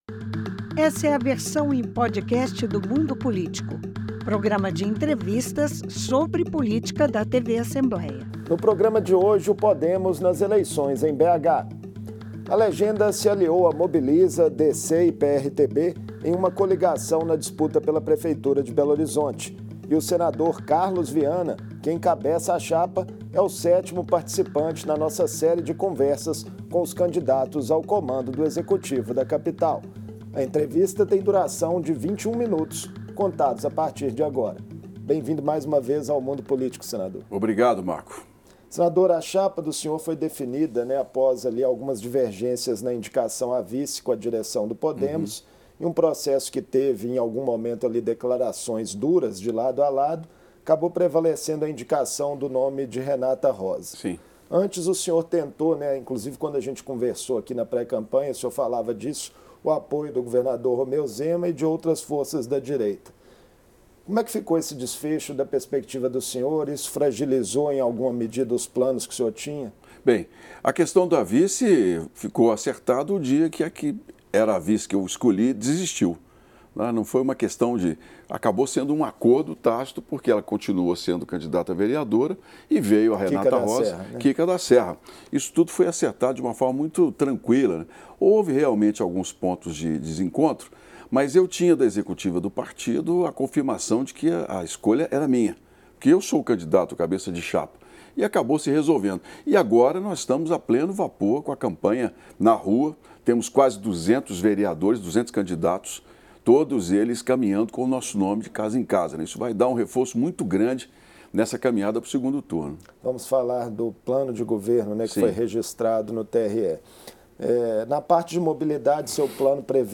entrevista o senador Carlos Viana que participa da corrida pela prefeitura da capital. Sobre o apoio da própria legenda e as rusgas que surgiram na pré-campanha, Viana se diz confiante nos “200 candidatos a vereador” do partido que fazem campanha para ele. Quanto a medidas para a cidade, defende a revisão dos contratos com as empresas de ônibus da capital, o aumento do transporte suplementar e a volta dos trocadores. Para o meio ambiente, Viana diz que pretende reduzir em 2 graus a temperatura da cidade e evitar enchentes com uma série de intervenções.